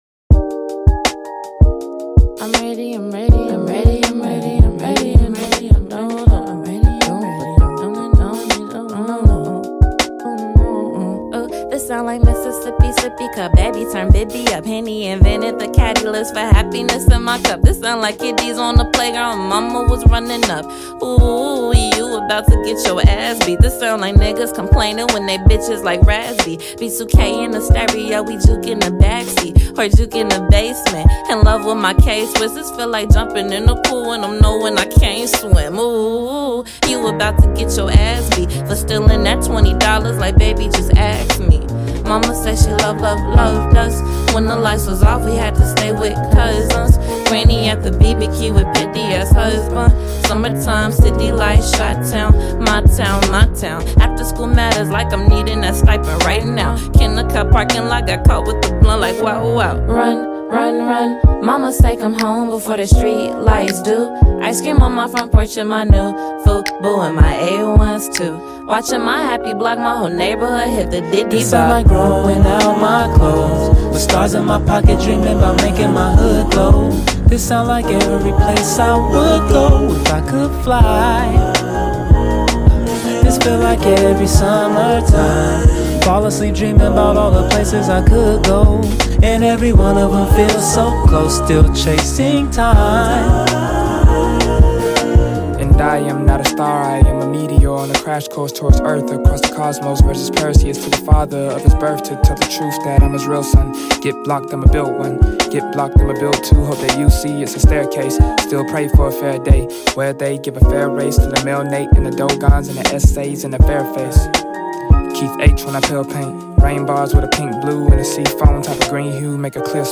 with its sweet beats and flows.